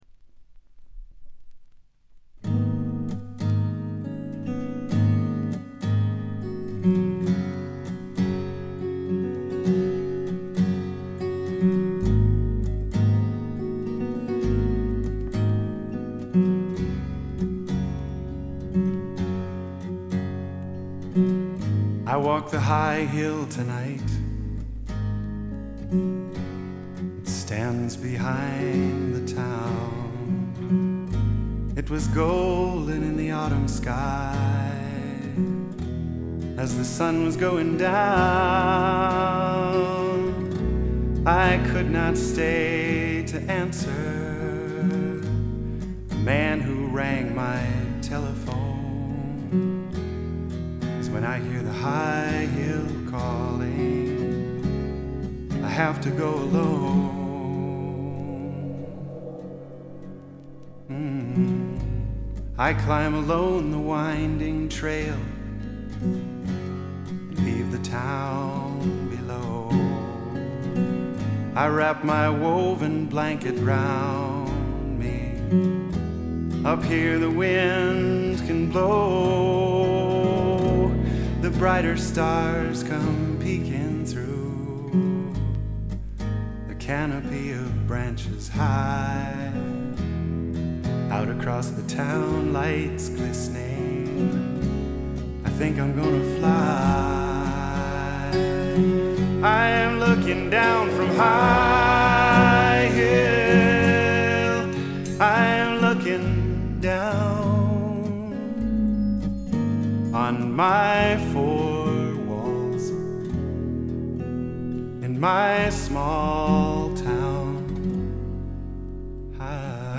試聴曲は現品からの取り込み音源です。
Guitar, Vocals